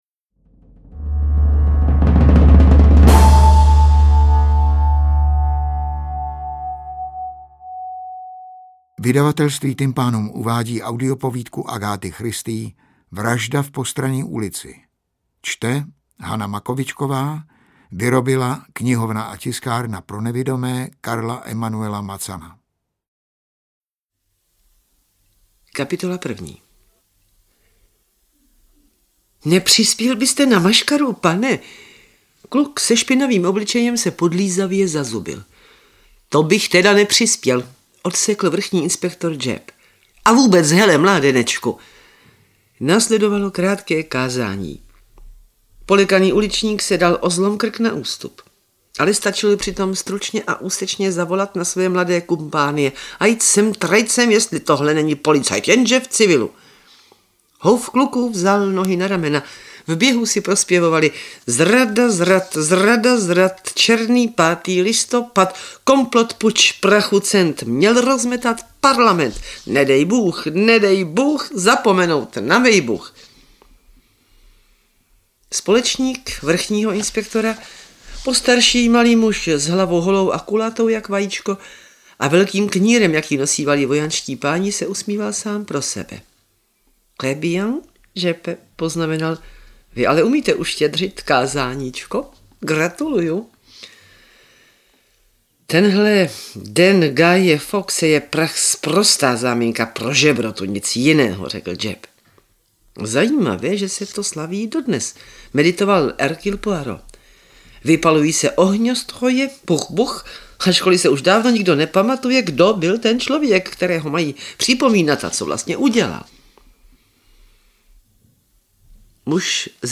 AudioKniha ke stažení, 16 x mp3, délka 2 hod. 33 min., velikost 142,0 MB, česky